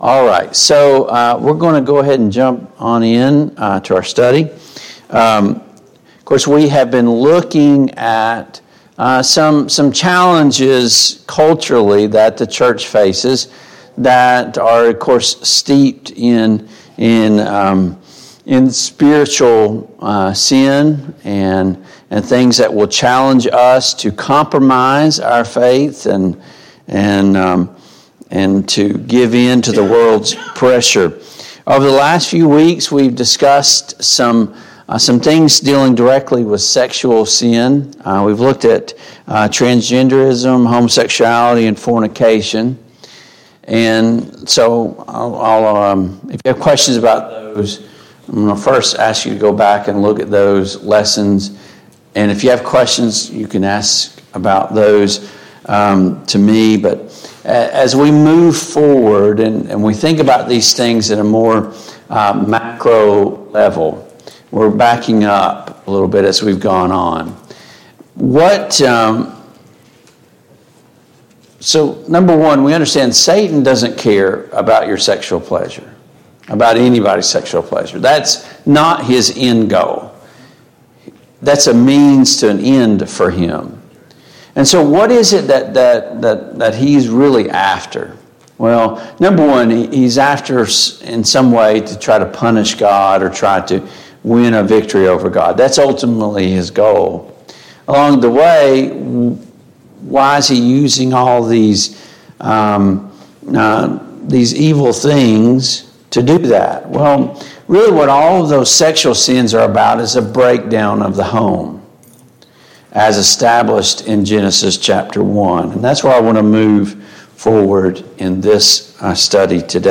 Service Type: Sunday Morning Bible Class Topics: Divorce and Remarriage